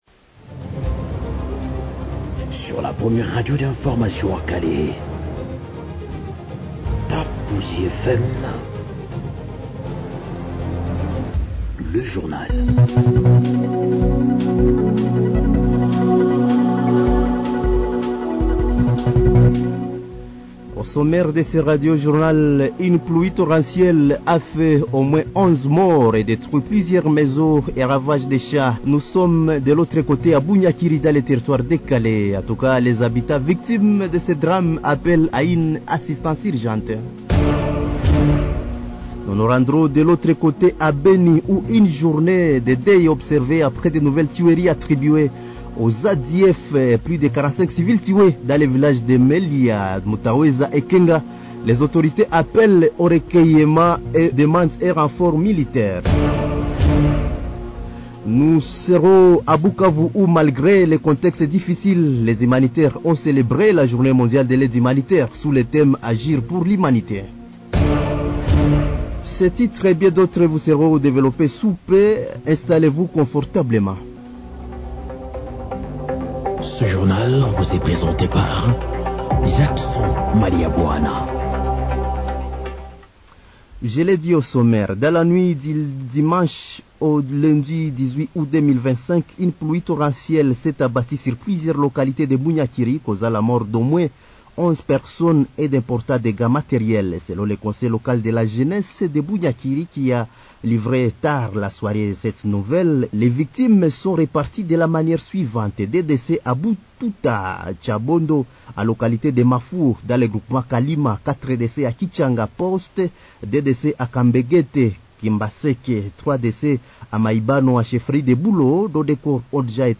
Journal